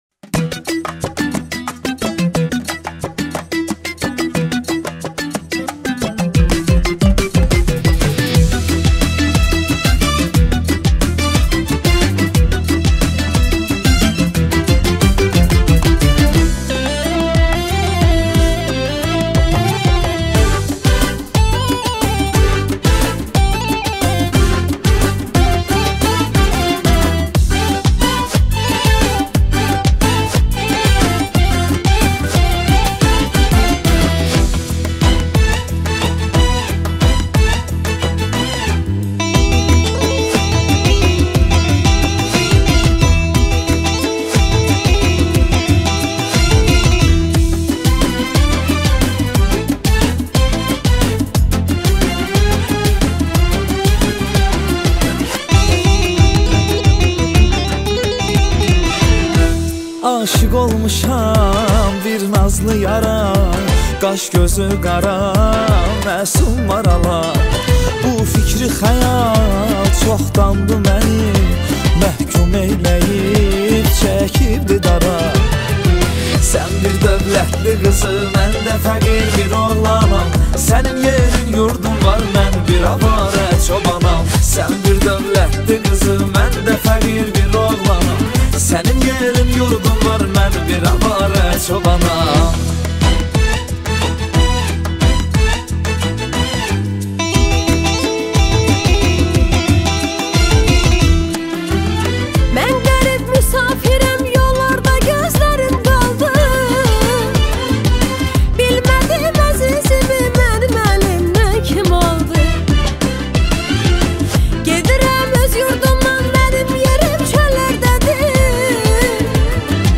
همخوانی زن و مرد